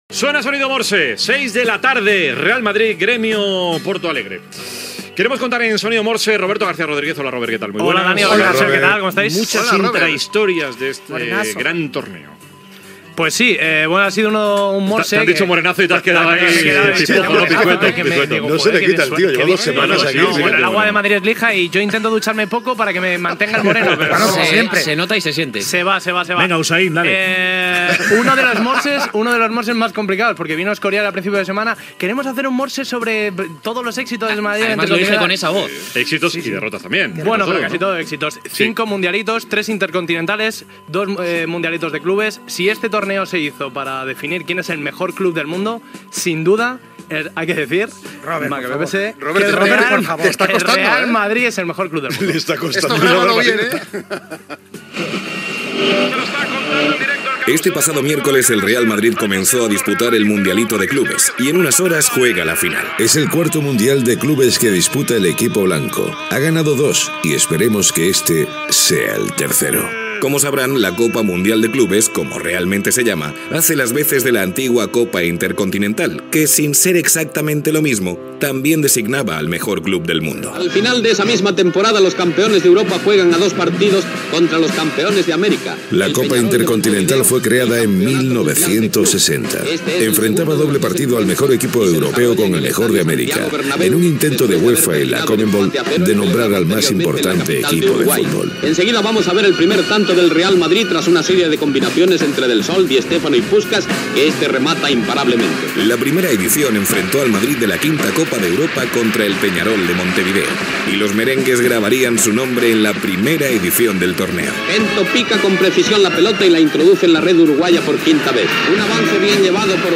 "Sonido Morse" dedicat a la Copa de Clubs i la Copa Intercontinental i el Real Madrid. Intervenció d'Alfredo Relaño
Esportiu